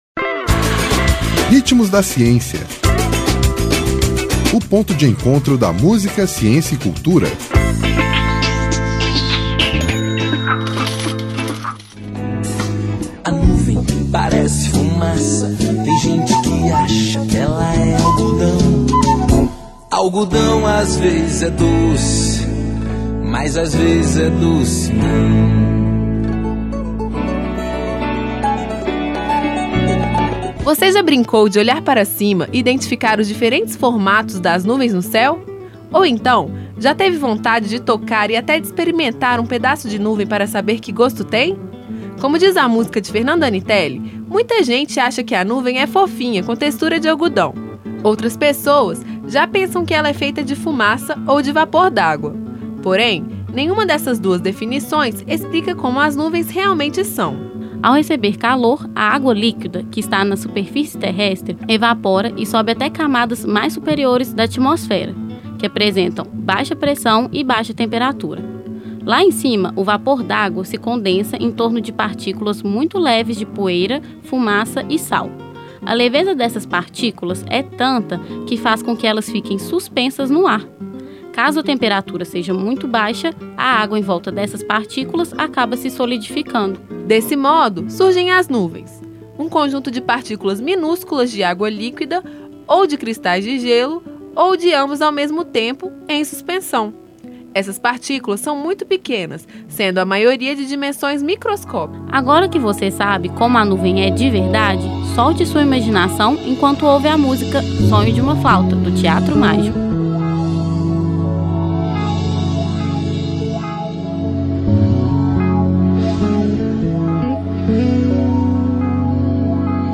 Nome da música: Sonho de uma flauta
Intérprete: Teatro Mágico